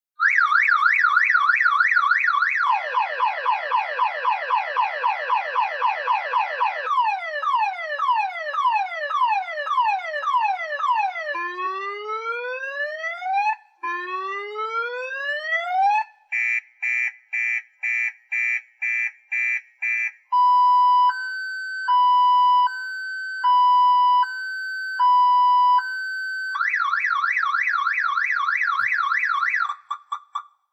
На этой странице собраны различные звуки автомобильных сигнализаций – от резких гудков до прерывистых тревожных сигналов.
Звук сработавшей автомобильной сигнализации при случайном касании